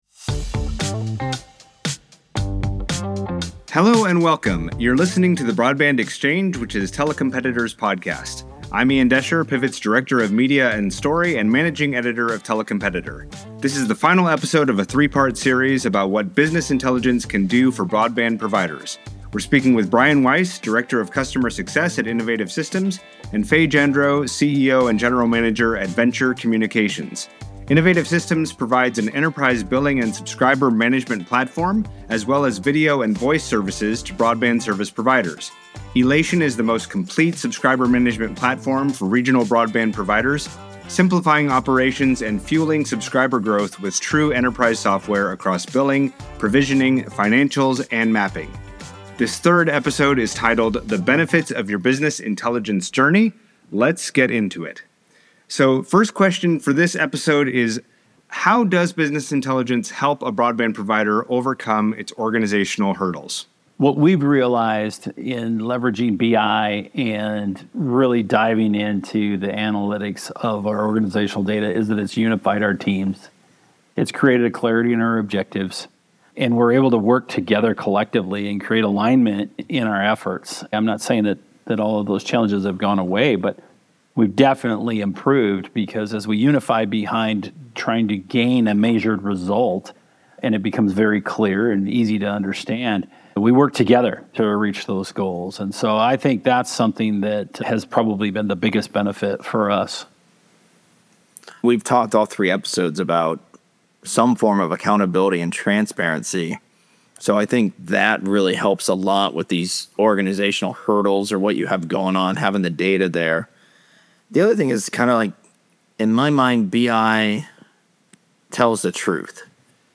Your Business Intelligence Journey: The Benefits What benefits can you expect to see once you implement business intelligence? Our conversation covers topics like improved support metrics, key performance indicators, and what business intelligence has to do with ARPU.